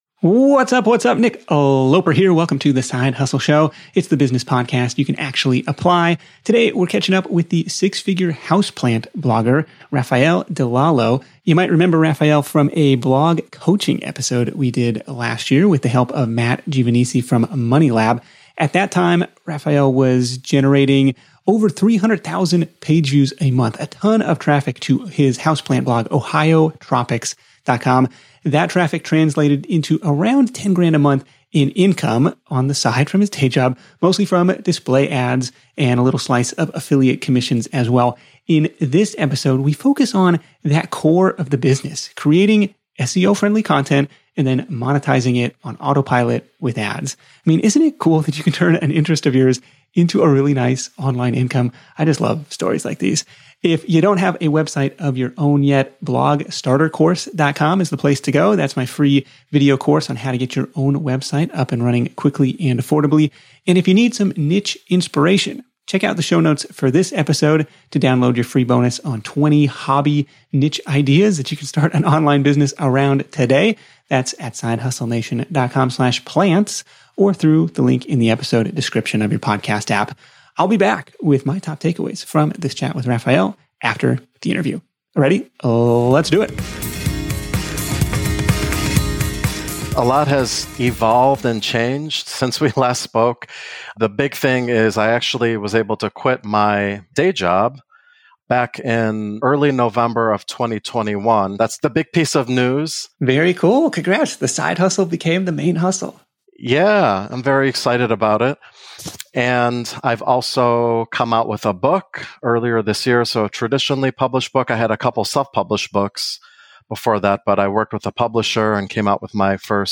The Side Hustle Show interview